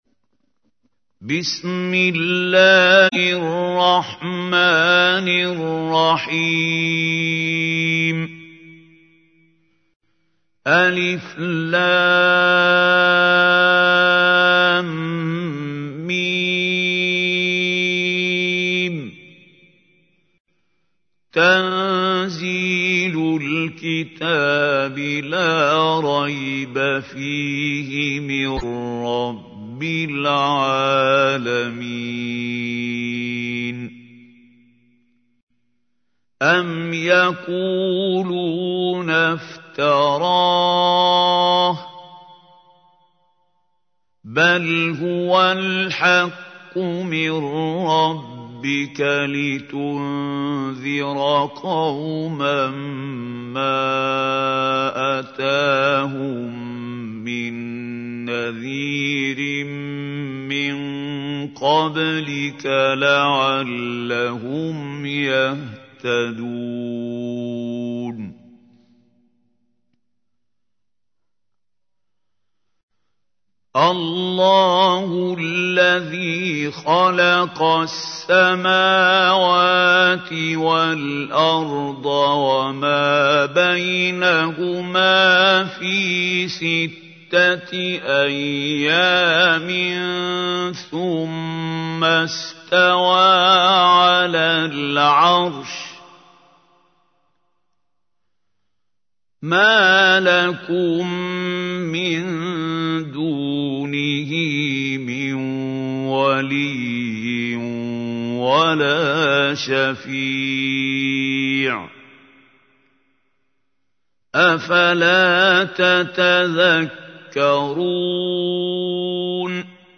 تحميل : 32. سورة السجدة / القارئ محمود خليل الحصري / القرآن الكريم / موقع يا حسين